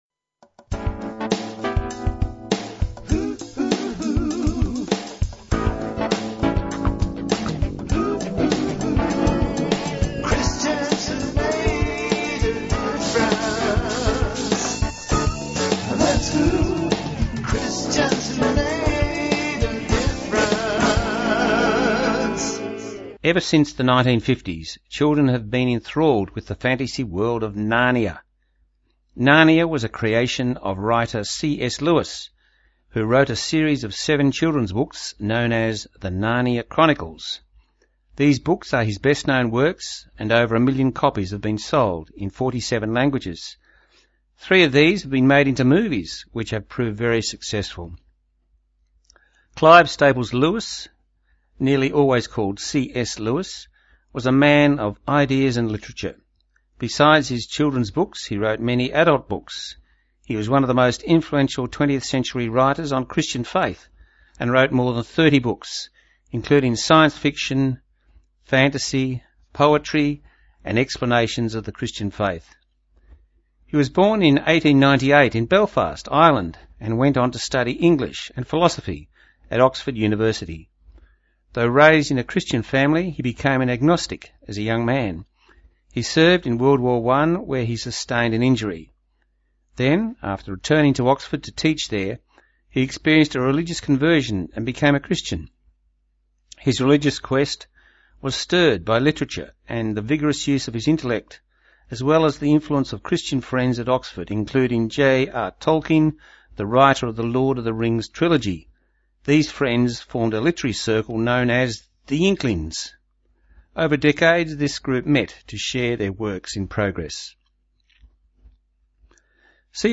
Broadcast on Songs of Hope on 13 September on Southern FM 88.3.